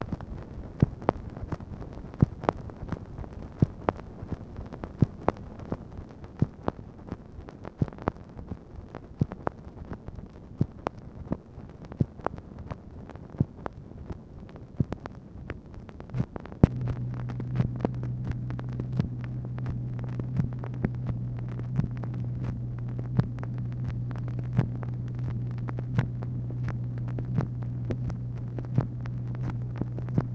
MACHINE NOISE, November 29, 1964